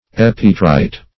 Epitrite \Ep"i*trite\, n. [Gr.